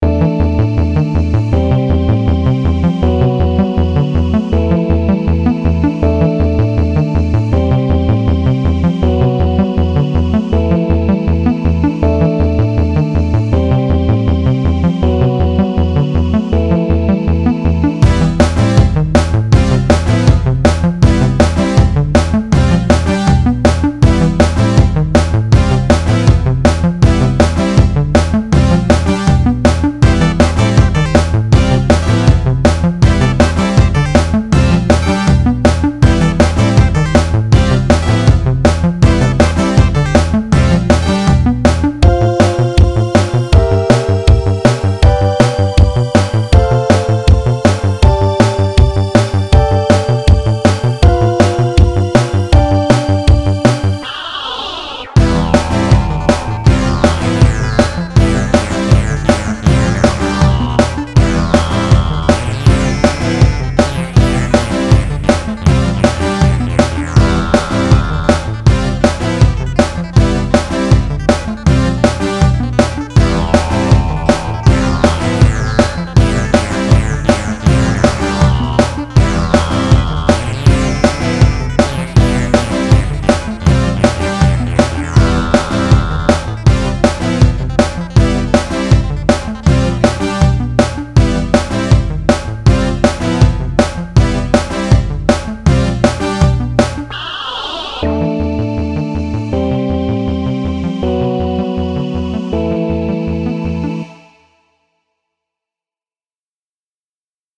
It's not "traditionally scary" but it was made for a space alien boss battle so I guess that counts as horror